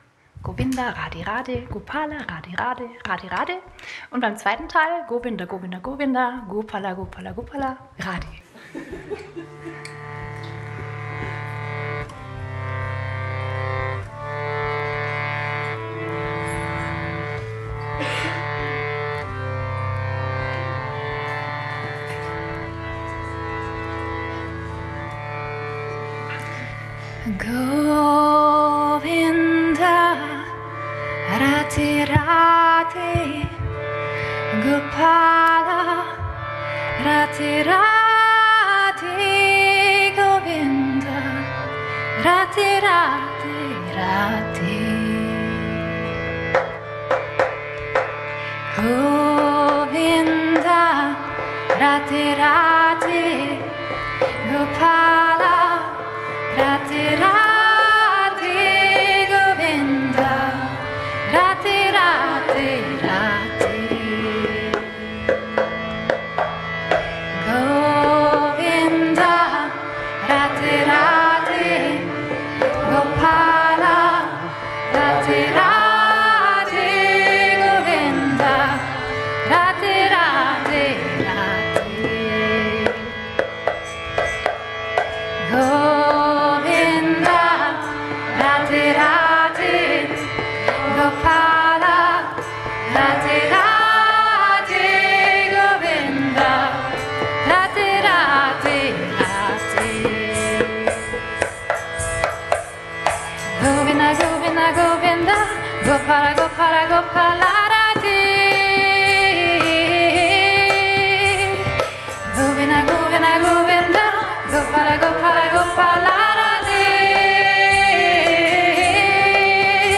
This mantra is a Vishnu Kirtan.